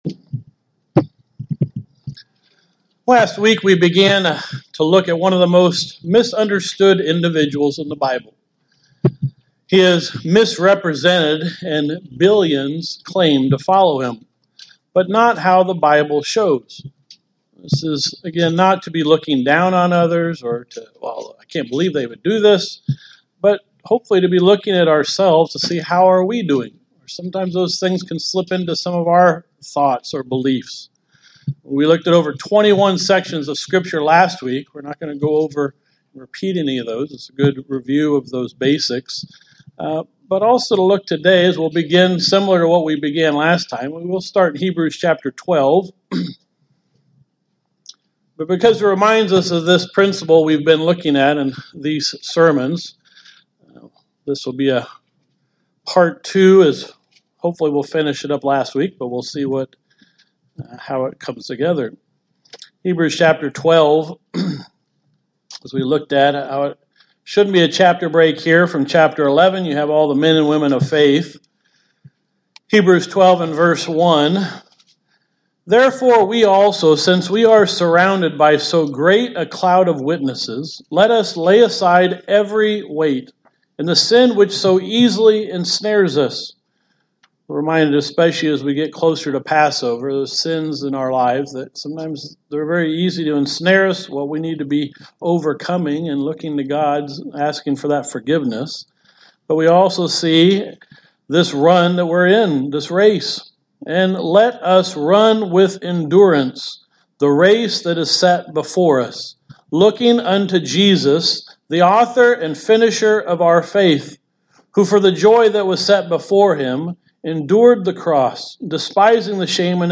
Continuing to look at following the perfect example of Jesus Christ in our lives as He followed the Father. This sermon goes through the first four misunderstandings people have of Jesus Christ.